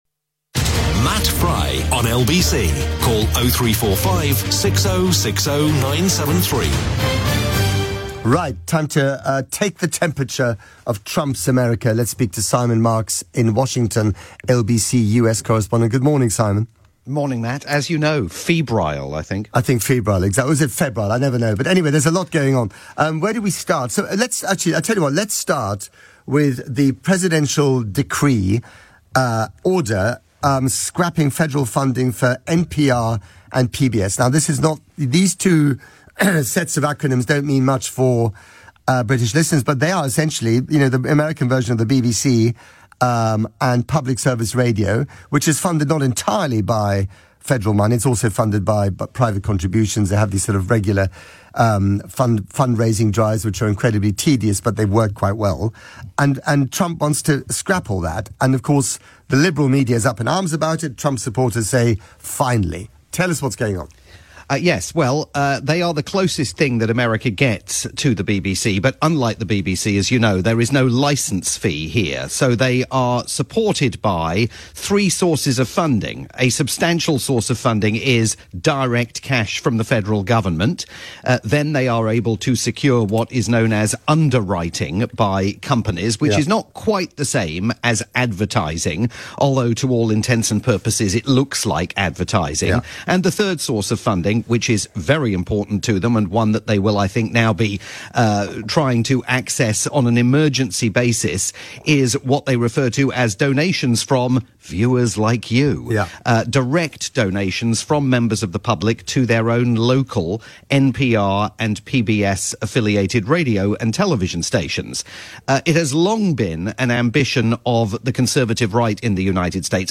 Saturday morning chat with Matt Frei on the UK's LBC.